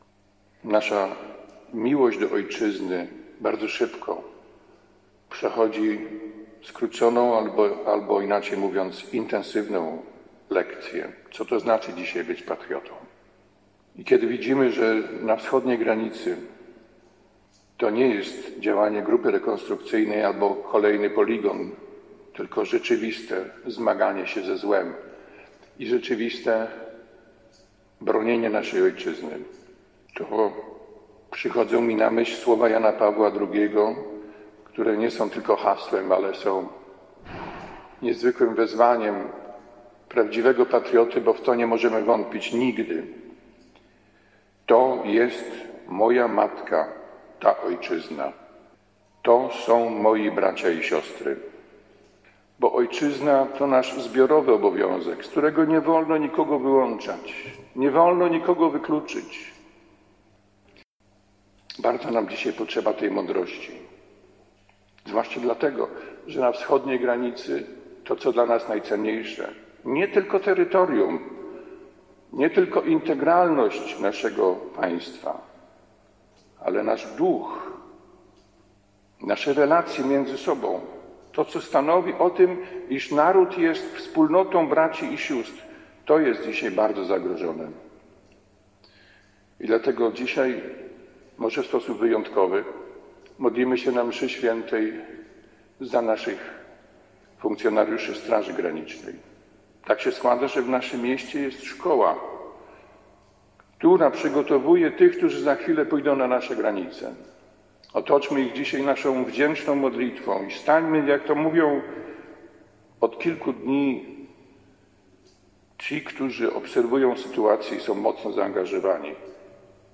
- Do granic naszej ojczyzny dobija się agresor, wykorzystując, w sposób absolutnie godny potępienia, ludzi. To wszystko wymaga nadzwyczajnej mądrości - mówił bp Krzysztof Zadarko podczas Mszy św. za ojczyznę w koszalińskiej katedrze.
bpKZniepodległa_kazanie.mp3